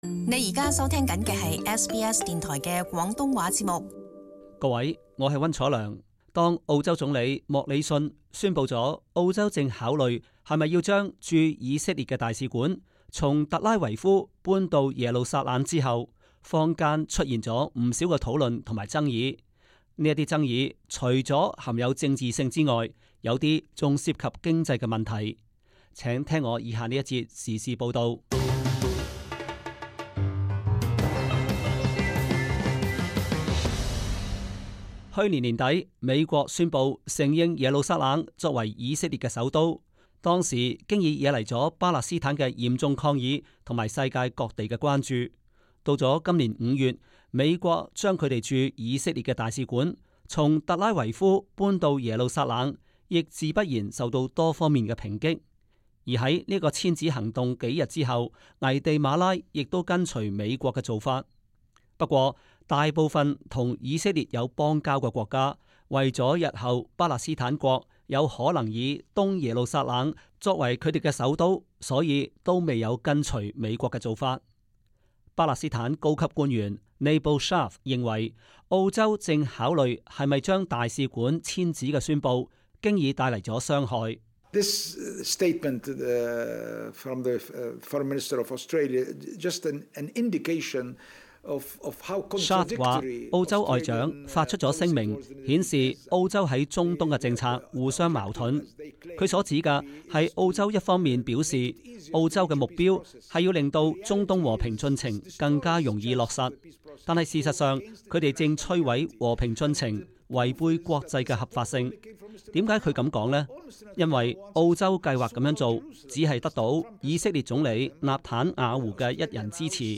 【時事報導】 巴勒斯坦及印尼不滿澳洲打算以色列使館遷址